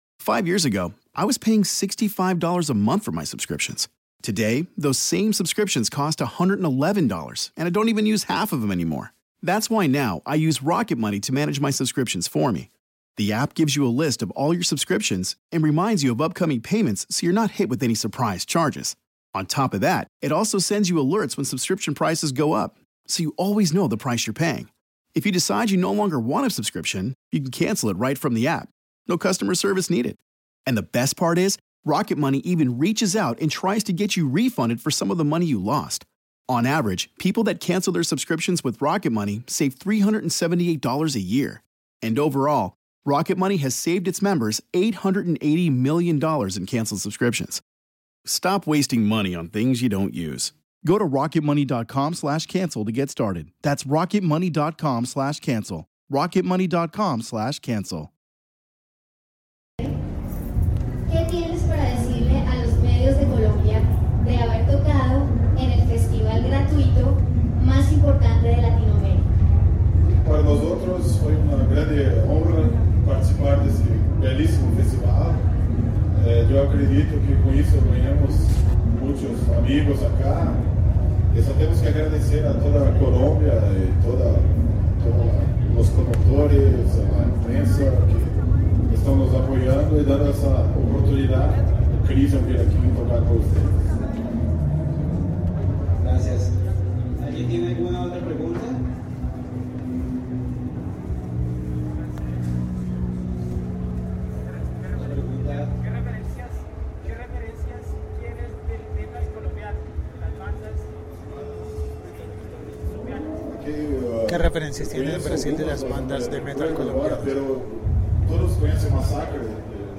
Krisiun rueda de prensa